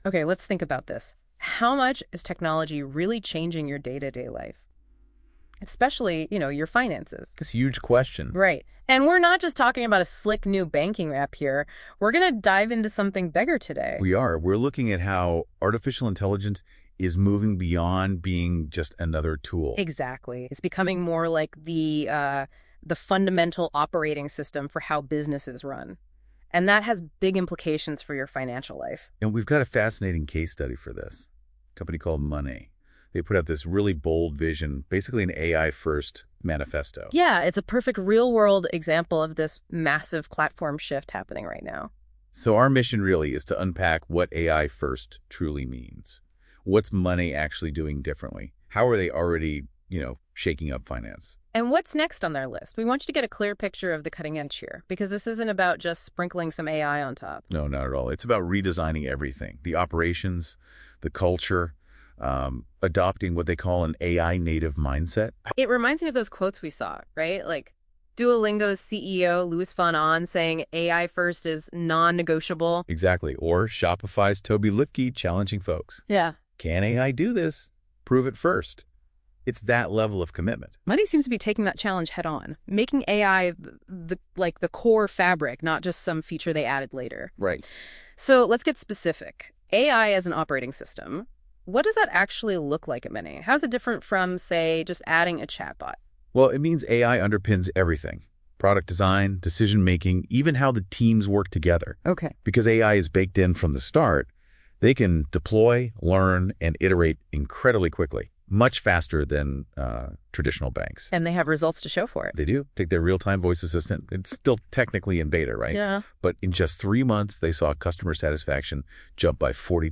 Yapay zeka, Monay'ın finansal "işletim sistemi" yaklaşımını analiz ediyor.